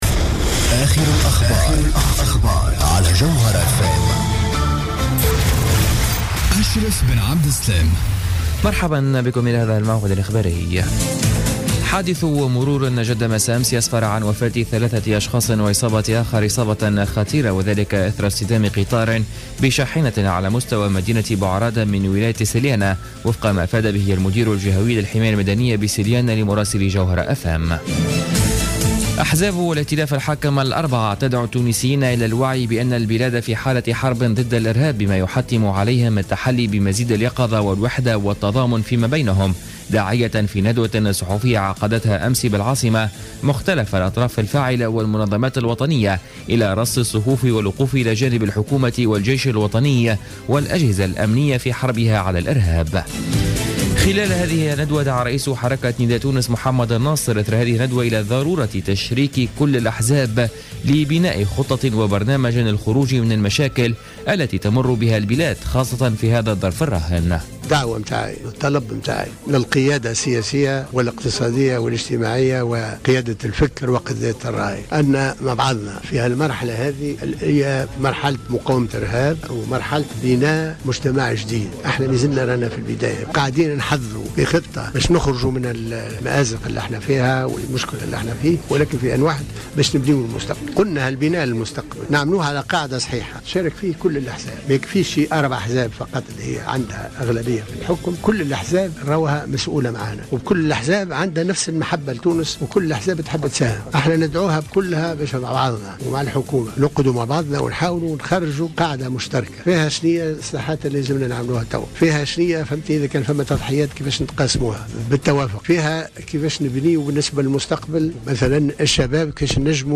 نشرة أخبار منتصف الليل ليوم الثلاثاء 14 جويلية 2015